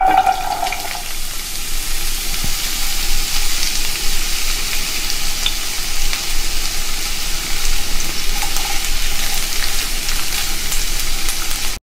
Stir Frying
Cooking
Stir-Frying-1-1.mp3